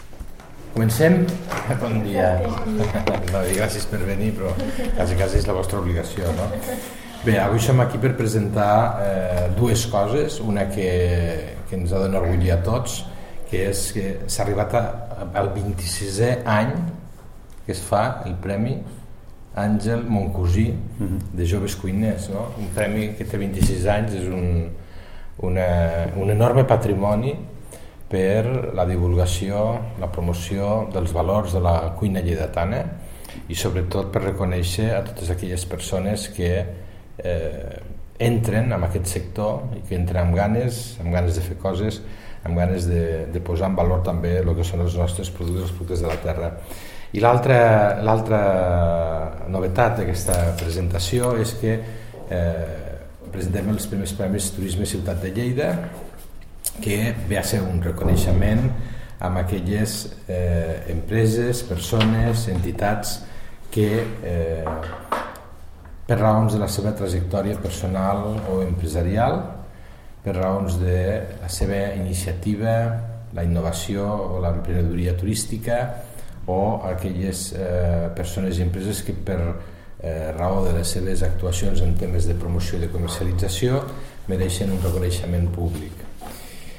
tall-de-veu-del-tinent-dalcalde-i-regidor-de-turisme-de-lleida-felix-larrosa